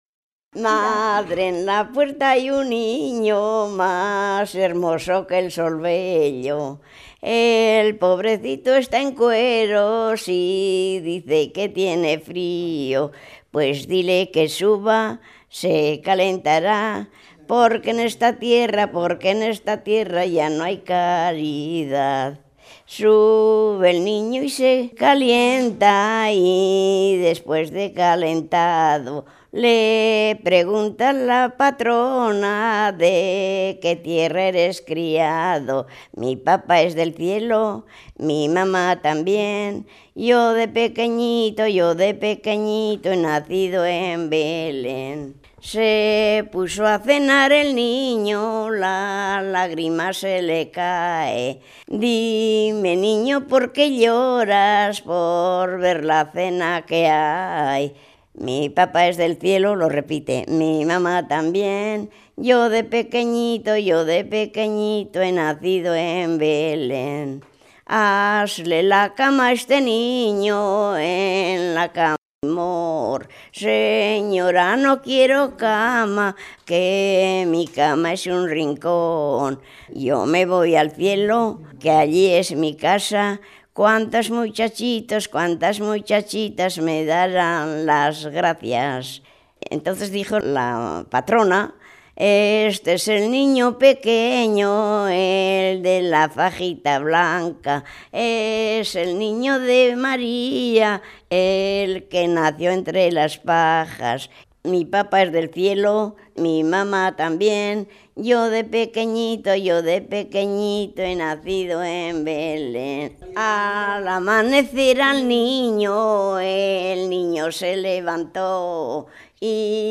Clasificación: Romancero
Lugar y fecha de grabación: Valdeperillo, 29 de agosto de 1999